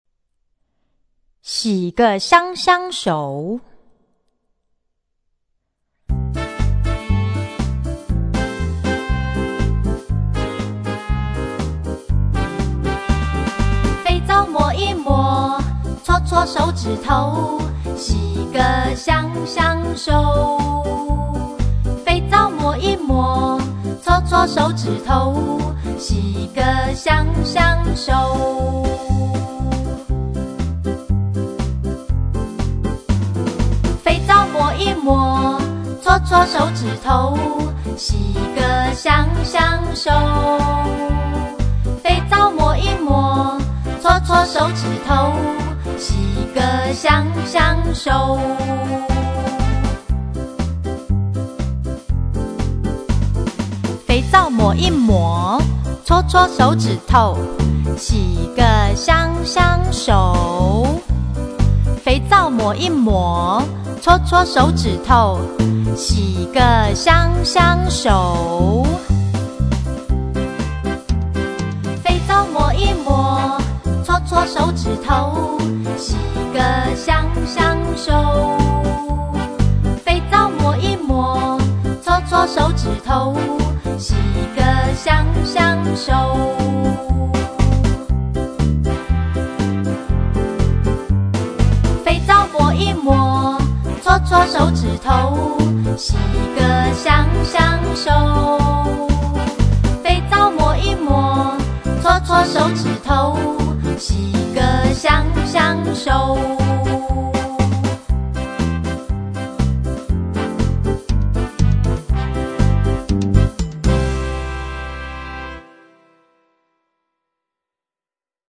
CD每首兒歌均譜寫輕快活潑的唱曲